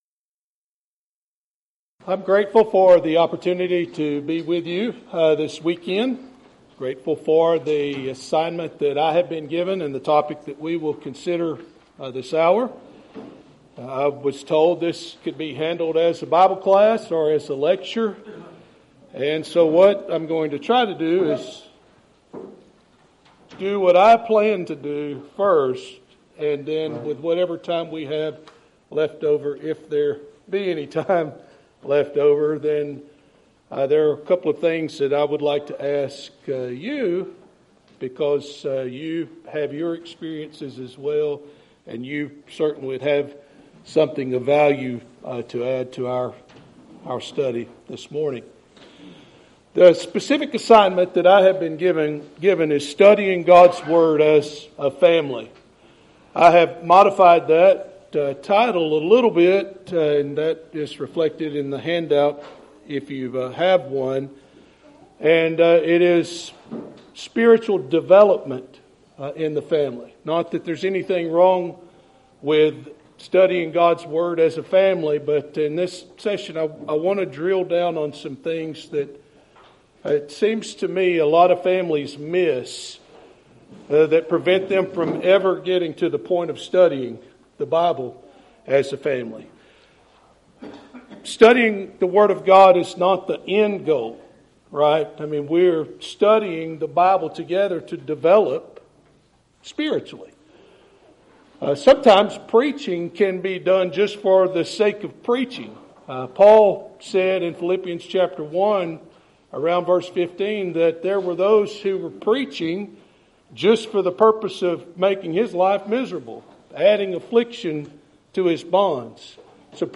Event: 3rd Annual Southwest Spritual Growth Workshop
lecture